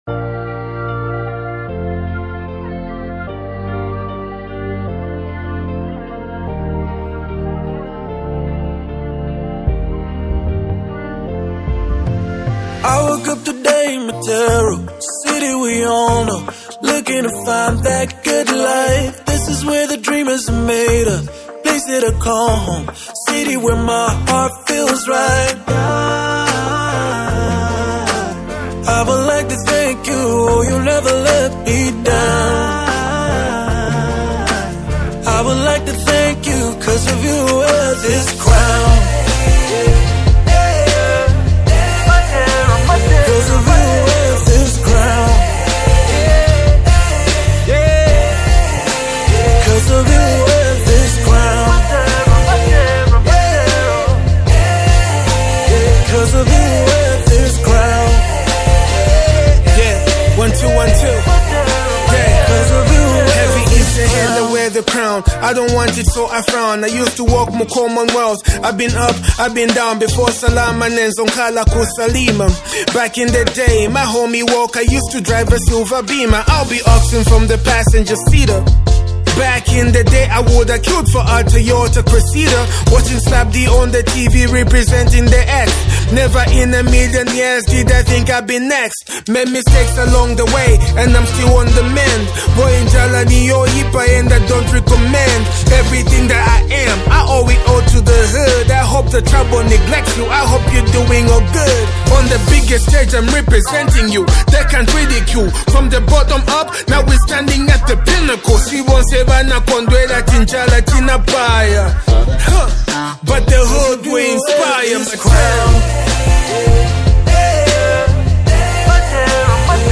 electrifying track
creating a track that resonates with energy and passion.
vibrant and soulful